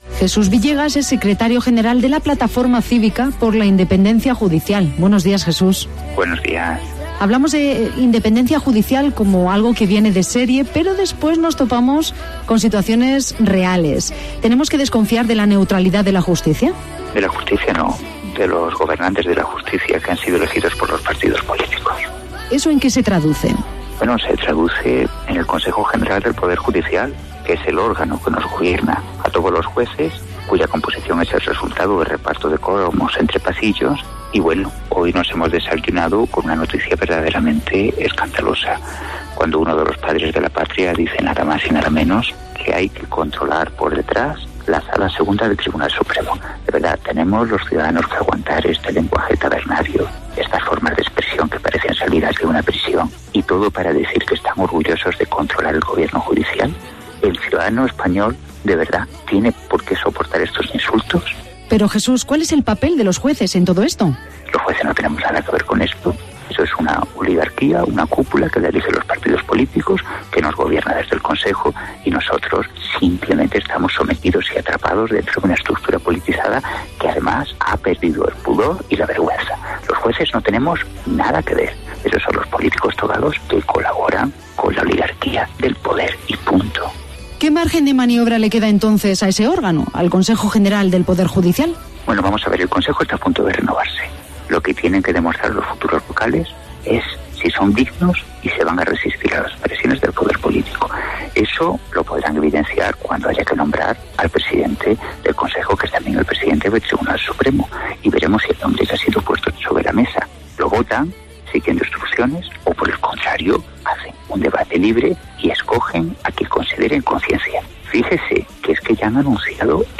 En este día de huelga general, convocada por las siete asociaciones de jueces y fiscales para reclamar la independencia de la Justicia, hablamos con Jesús Villegas, Juez Decano de Guadalajara y, además, Secretario General de la Plataforma Cívica por la Independencia Judicial. Villegas ha calificado de bochornoso el anuncio del nombre del nuevo presidente del Consejo General del Poder Judicial antes de que los vocales responsables de elegirlo hayan votado y a éstos les ha instado a demostrar su dignidad resistiéndose a las presiones de los "políticos togados" a la hora de elegir al presidente del CGPJ, que también será presidente del Tribunal Supremo.